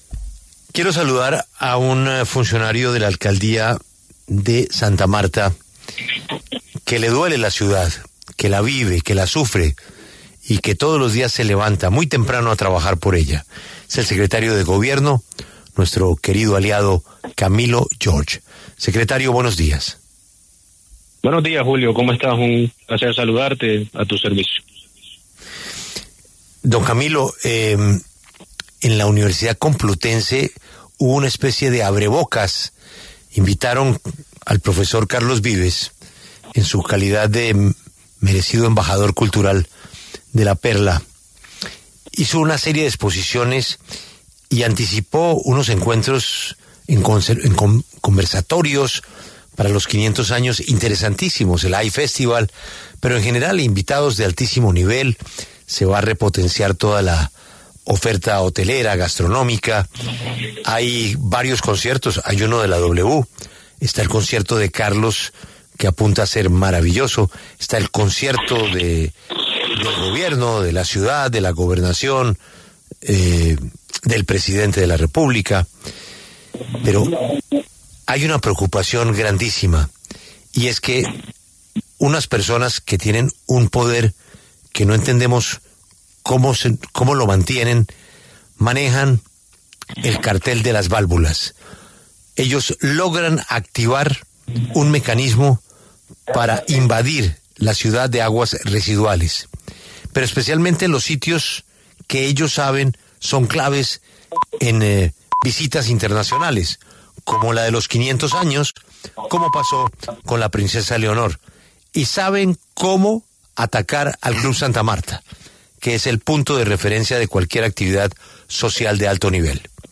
En diálogo con La W, el secretario de Gobierno de Santa Marta, Camilo George, habló sobre la denuncia hecha por el Distrito respecto al saboteo de las redes de alcantarillado de la ciudad, próxima a cumplir 500 años.